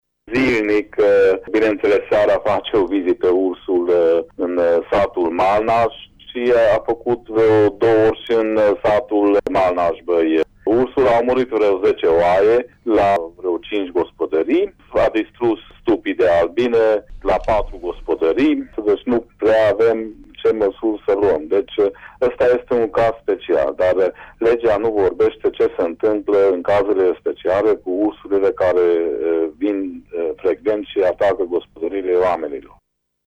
De la finalul lunii martie, locuitorii comunei covăsnene Malnaș sunt terorizați de un urs care dă iama în gospodăriile lor și face ravagii. Primarul comunei, Kasléder Lászlo, despre pagubele provocate de animalul sălbatic: